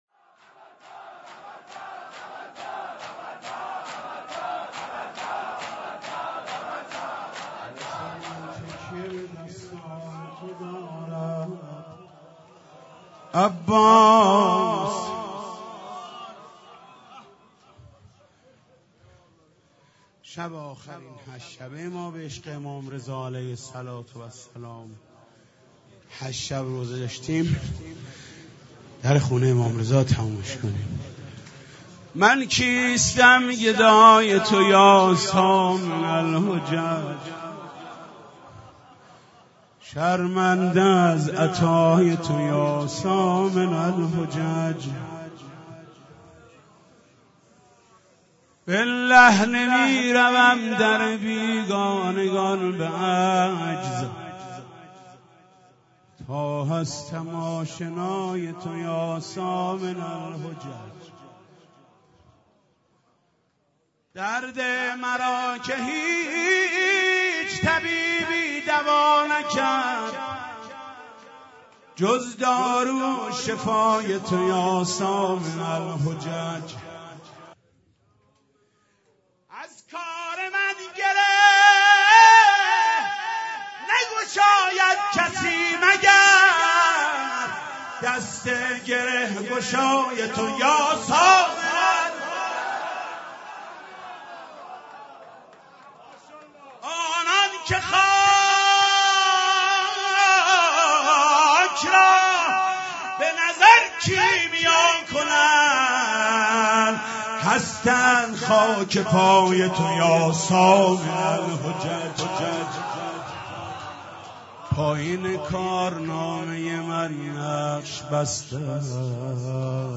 مراسم شب بیستم و سوم ماه مبارک رمضان با مداحی حاج محمود کریمی در امام زاده علی اکبر چیذر برگزار گردید